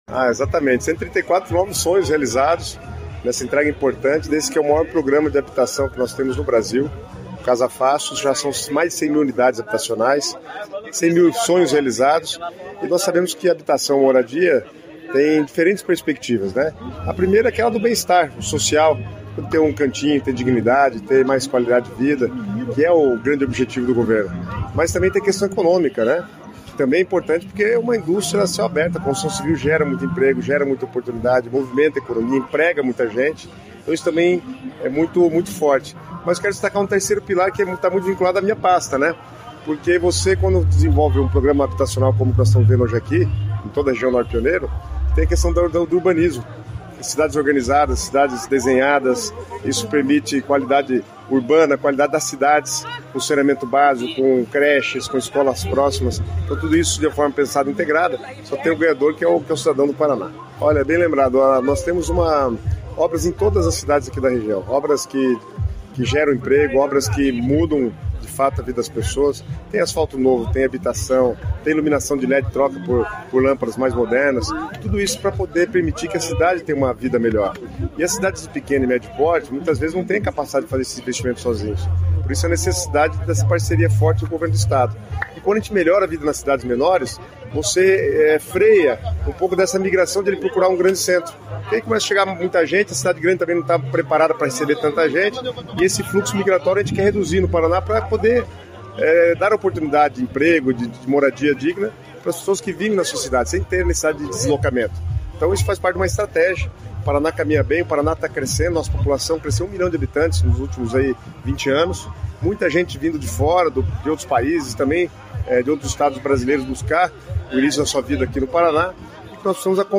Sonora do secretário das Cidades, Guto Silva, sobre a entrega de residencial com 134 casas em Andirá